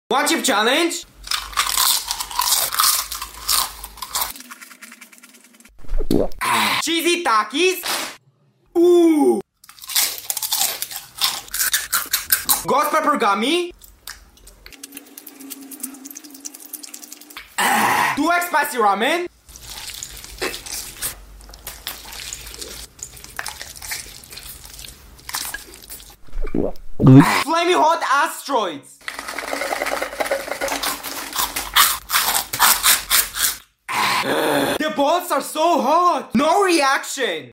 Spiciest Food Emoji ASMR! 🥵🔥 sound effects free download